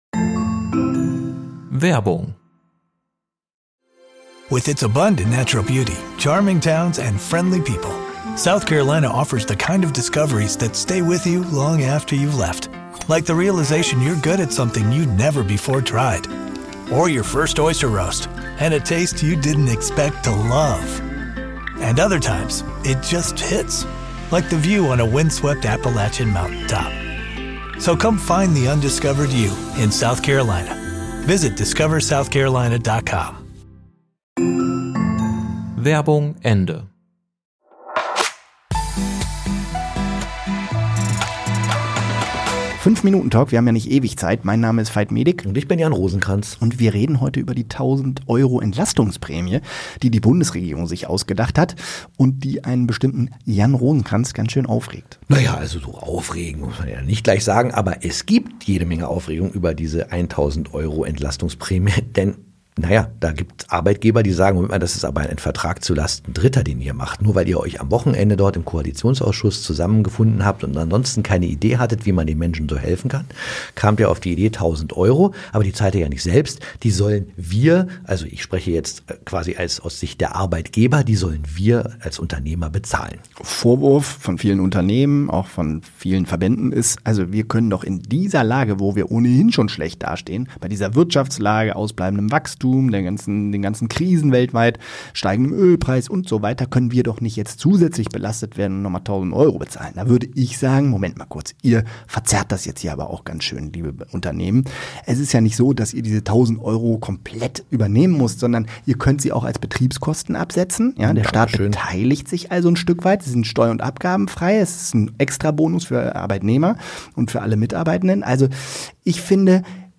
5-Minuten-Talk – wir haben ja nicht ewig Zeit